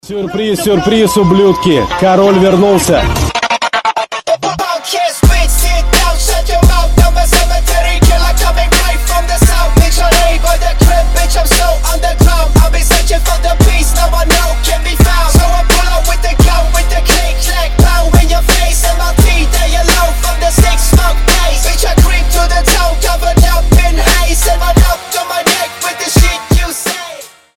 • Качество: 320, Stereo
жесткие
мощные басы
Alternative Rap
рэпкор